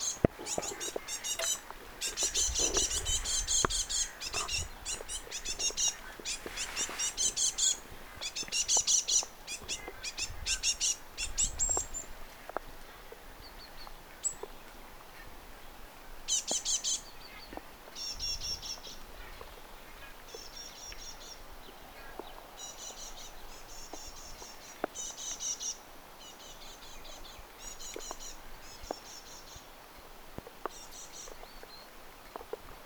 vielä pesue pikkuisia sinitiaisen poikasia
viela_pesue_pikkuisia_sinitiaisen_poikasia.mp3